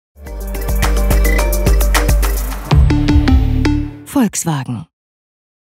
スピード感と鼓動感を感じさせるものが多い。